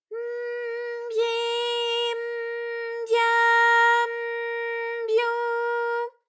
ALYS-DB-001-JPN - First Japanese UTAU vocal library of ALYS.
by_m_byi_m_bya_m_byu.wav